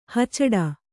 ♪ hacaḍa